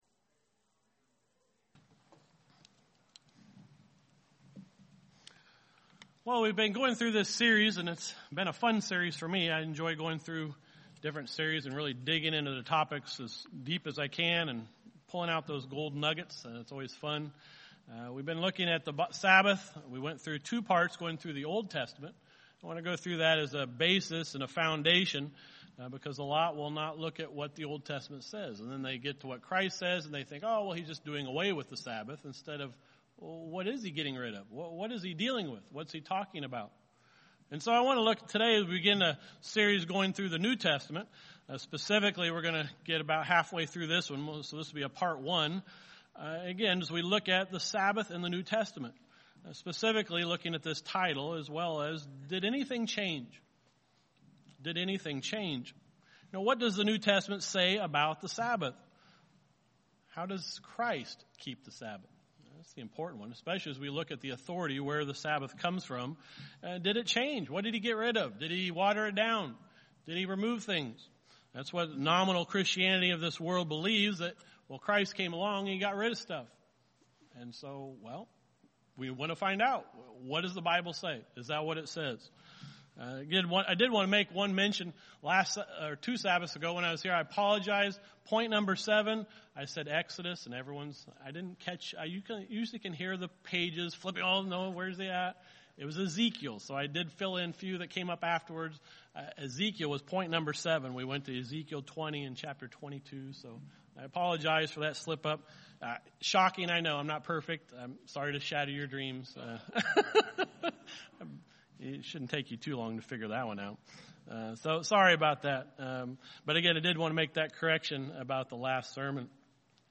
The third in a series on the Sabbath. The first two sermons looked at the Sabbath in the Old Testament now this sermon begins to go through the Sabbath in the New Testament and what we can learn from it.